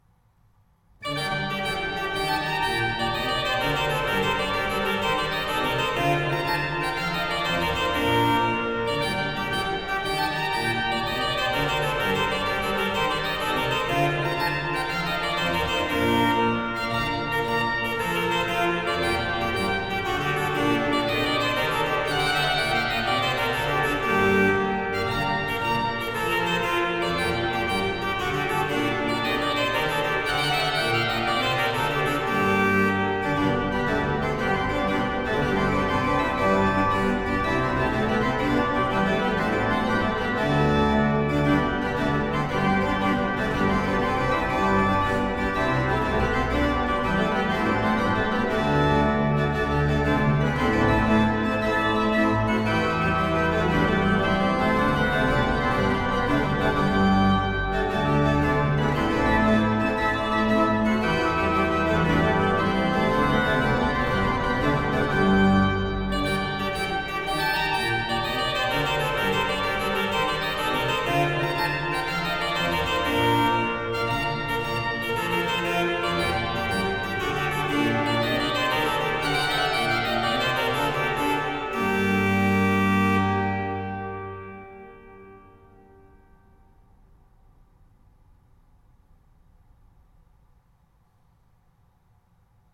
CD enregistré en l'église de Preignac